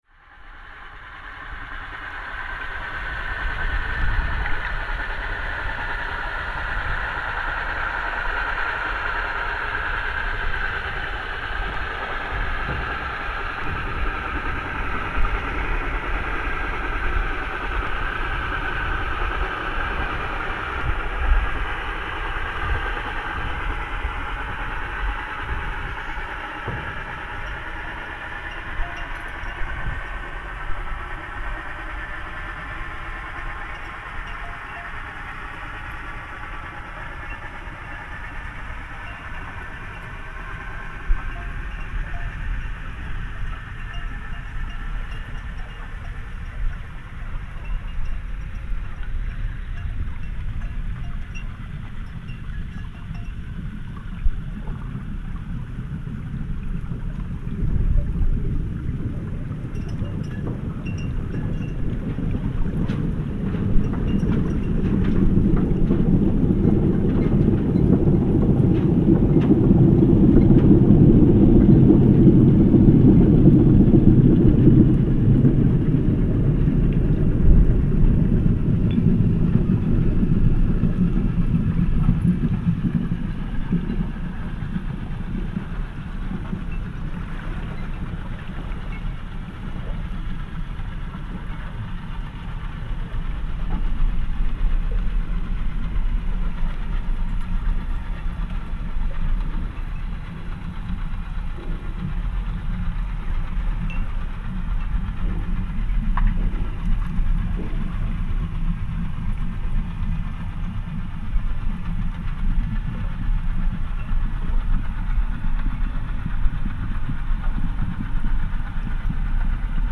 thames near blackfriars
london, england
thames.mp3